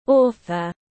Tác giả tiếng anh gọi là author, phiên âm tiếng anh đọc là /ˈɔːθər/.
Author /ˈɔːθər/